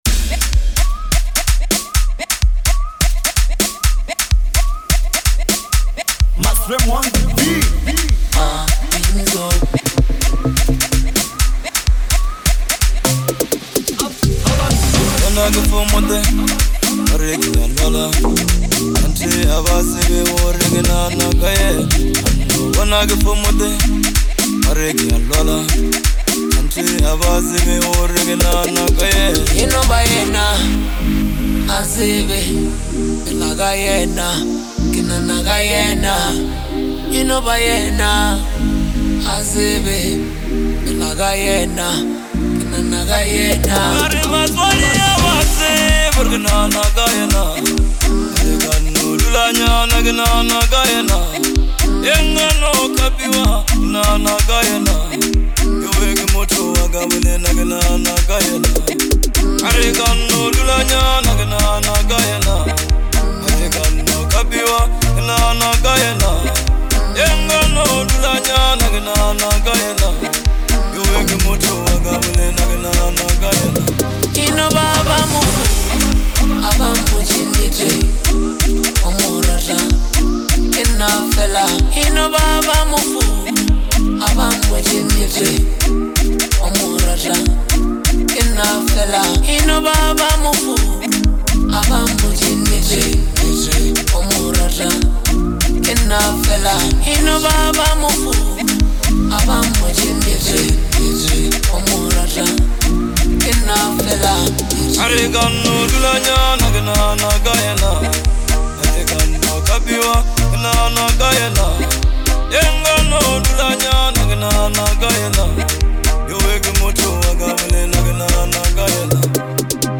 heartfelt and emotional song
soulful lekompo anthems